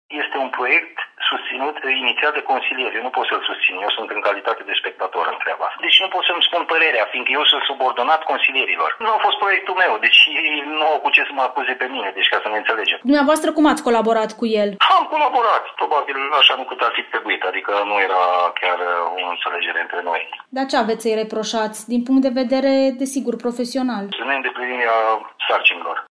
Primarul PMP de Uivar, Bogdan Săvulescu, spune că nu el a inițiat proiectul de hotărâre pentru revocarea viceprimarului PNL, dar admite și că nu s-a înțeles foarte bine cu acesta.